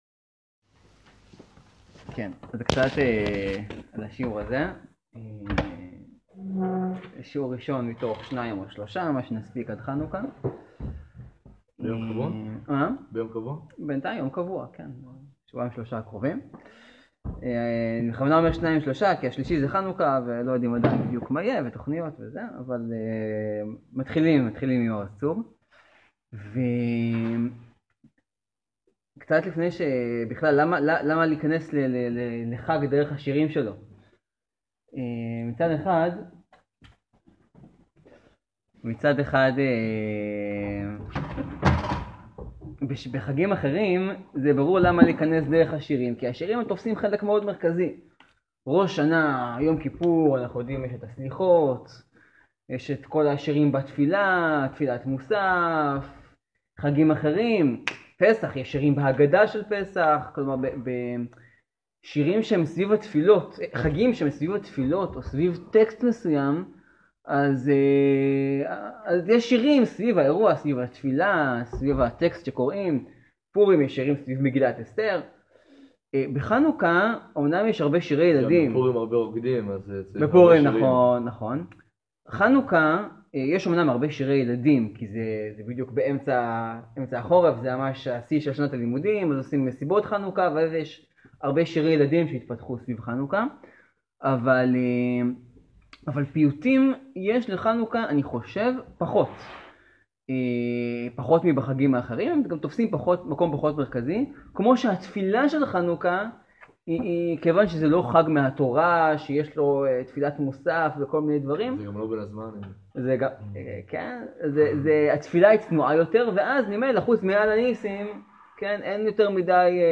מי אמר שמעוז צור זה שיר לחנוכה, מה עושים בו פרעה והמן, ומה זה בדיוק מצר המנבח? חלק ראשון בסדרת פודקאסטים לחנוכה, מתוך שיעורים שהועברו בקדימה.